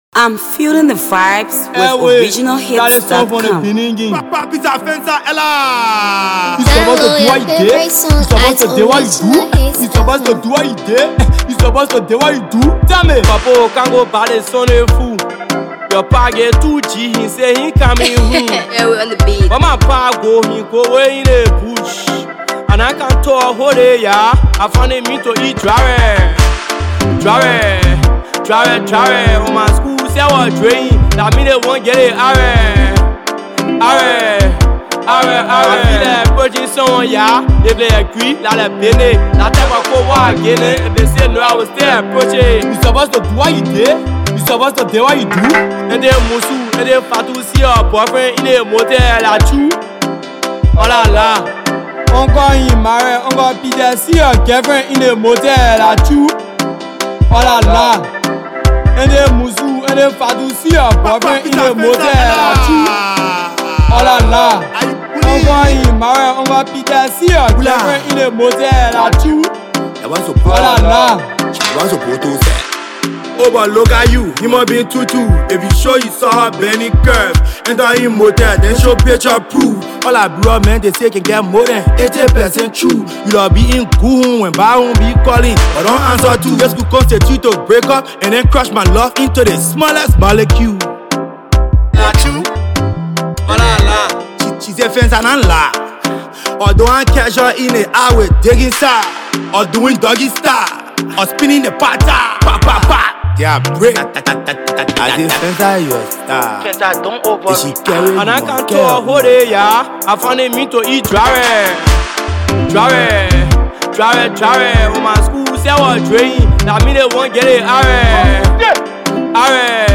AfroAfro PopLATEST PLAYLISTMusicTOP SONGSTrapco